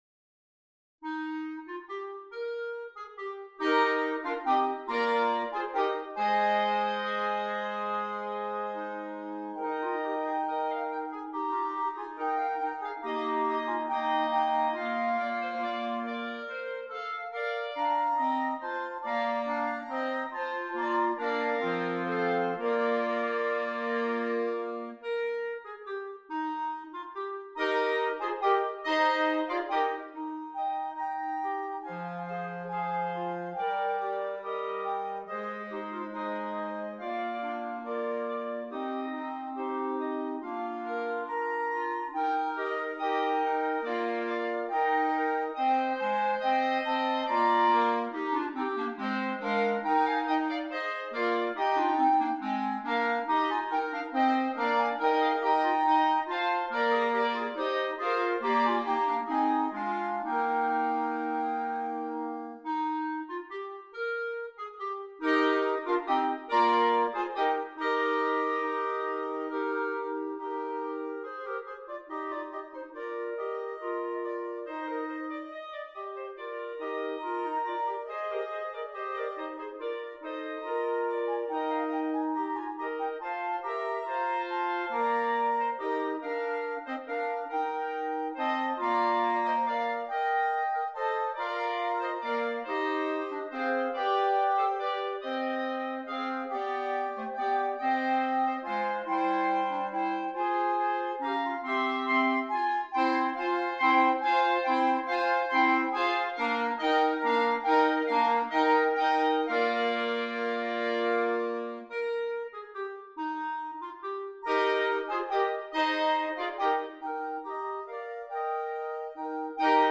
6 Clarinets